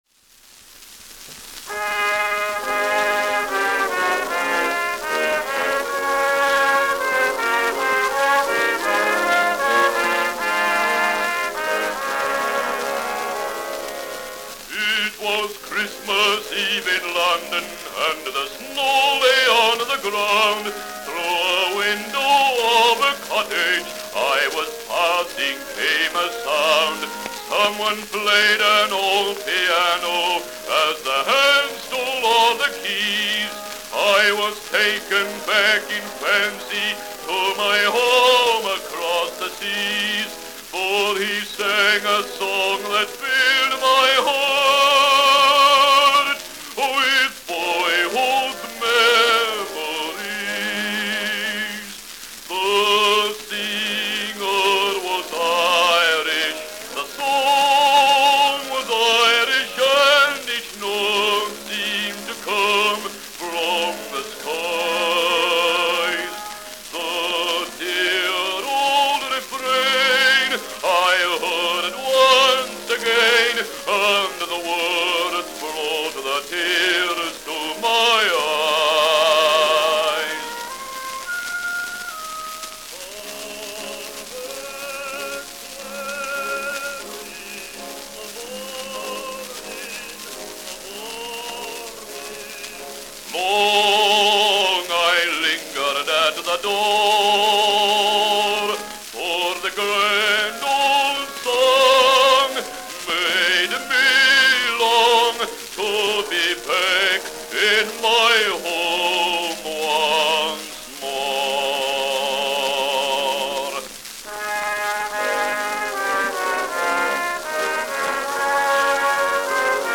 The singer was Irish.
Bass solo with orchestra accompaniment.
Popular music—1901-1910.